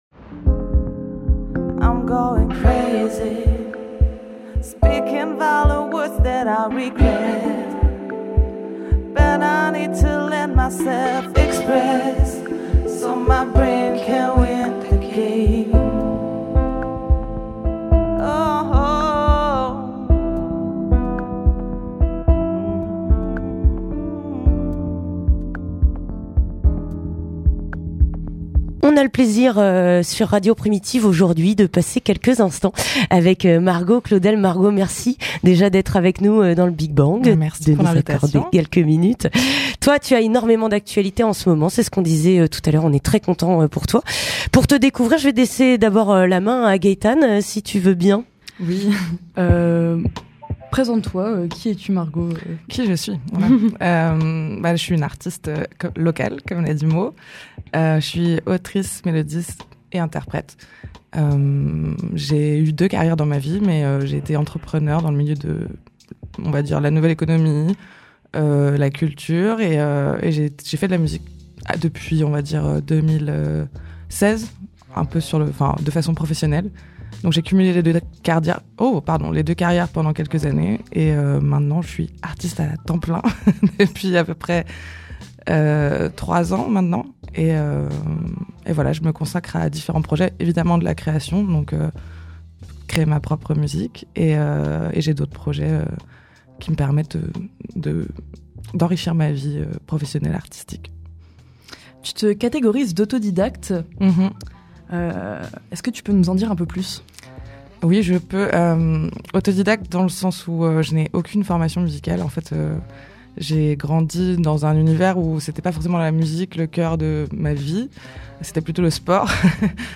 Interview du 06 mars (15:39)